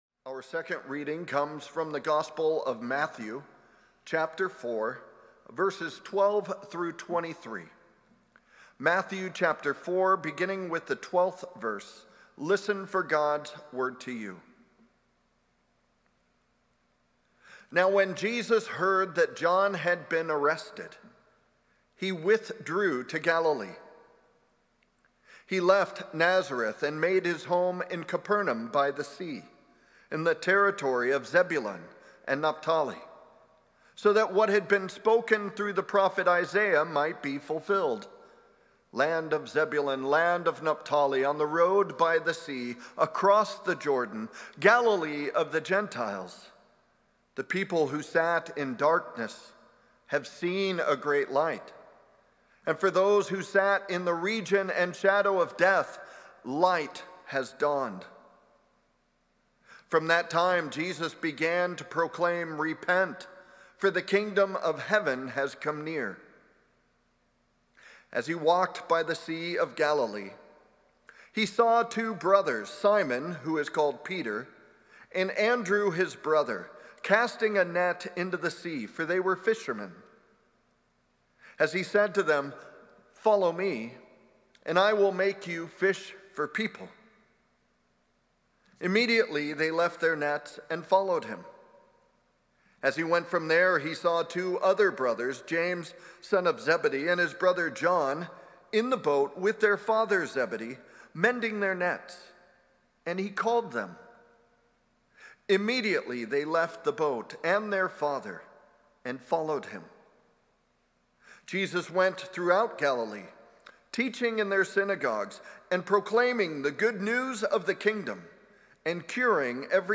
1-25-26+Sermon.mp3